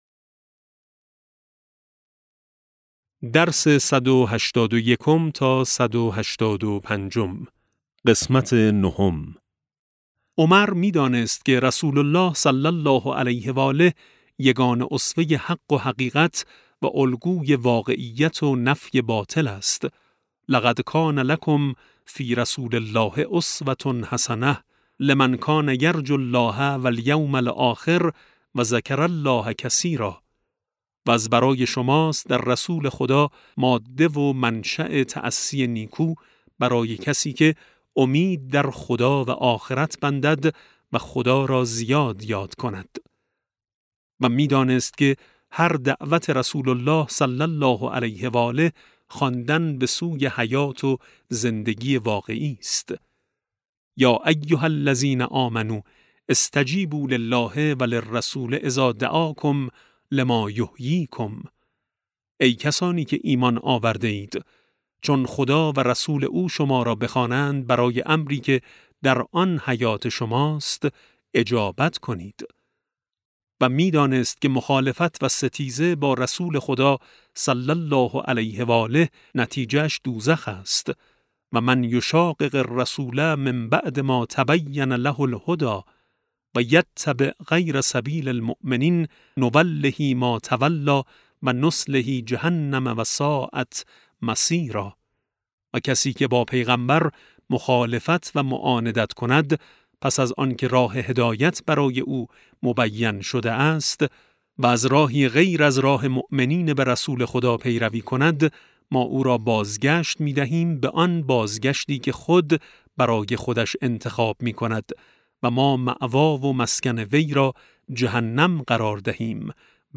کتاب صوتی امام شناسی ج 13 - جلسه9